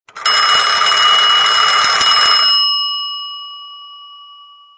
old-payphone-ring.mp3